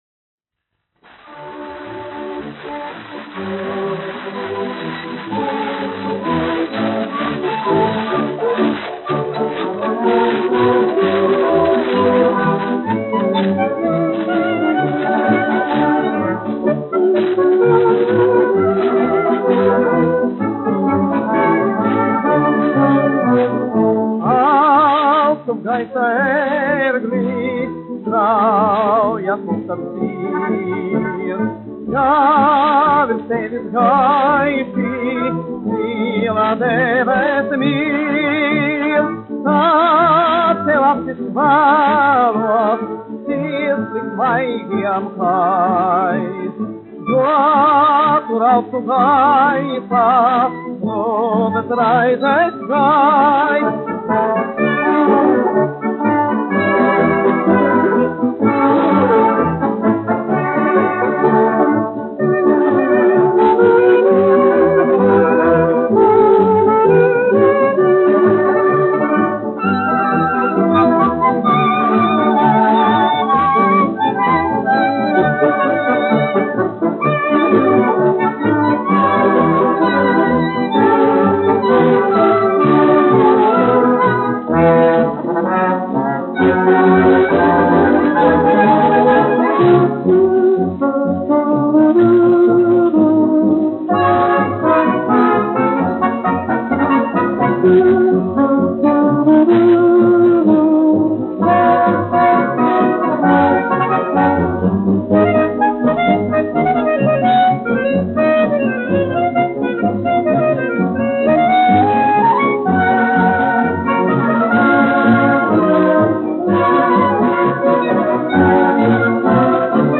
1 skpl. : analogs, 78 apgr/min, mono ; 25 cm
Marši
Fokstroti
Populārā mūzika -- Latvija
Latvijas vēsturiskie šellaka skaņuplašu ieraksti (Kolekcija)